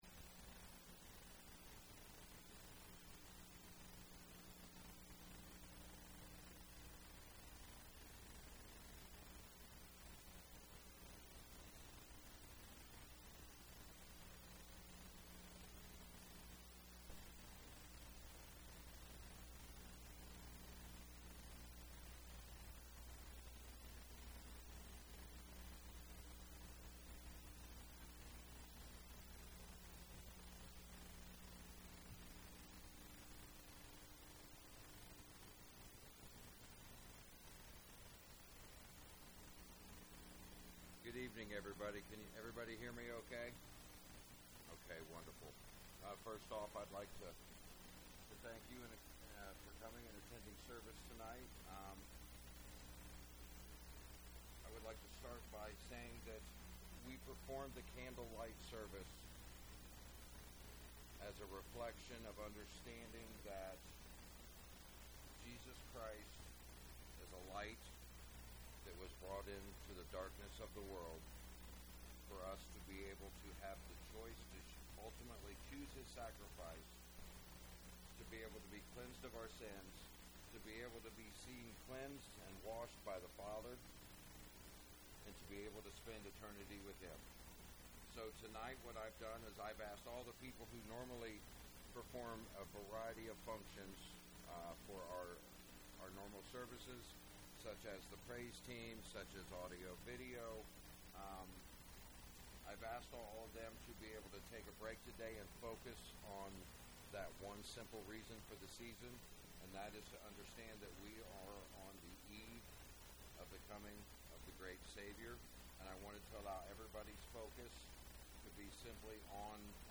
I do want to apologize for the audio. Evidently, we were having issues with some of our microphones this evening.